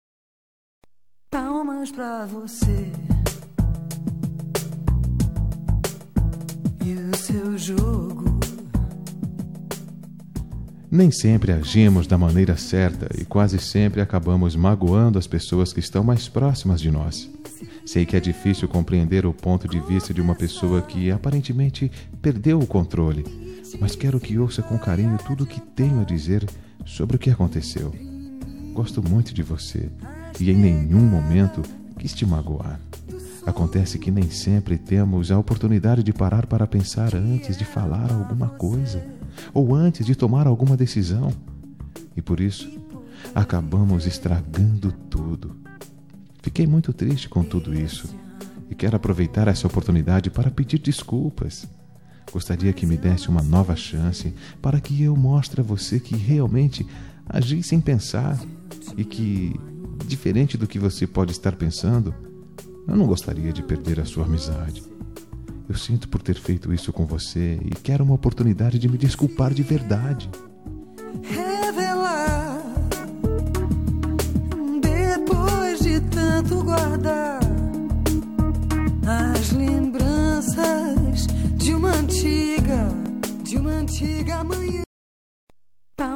Telemensagem de Desculpas Geral – Voz Masculina – Cód: 5474